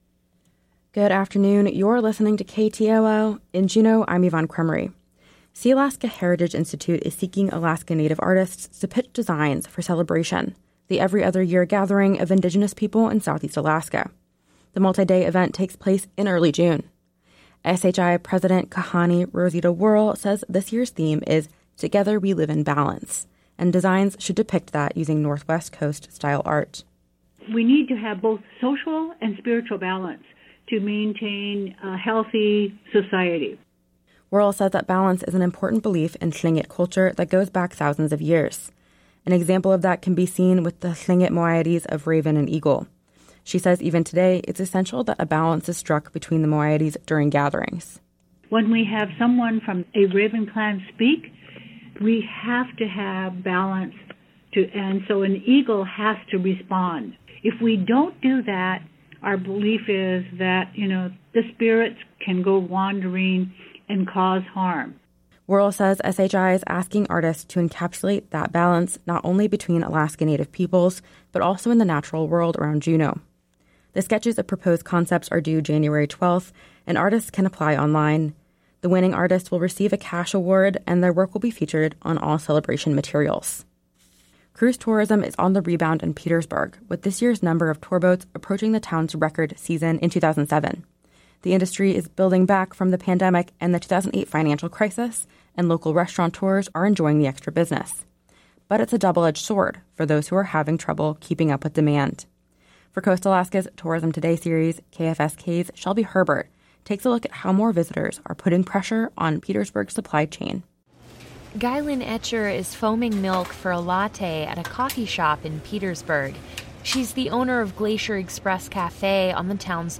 Newscast – Wednesday, Jan. 3, 2023